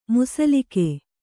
♪ musalike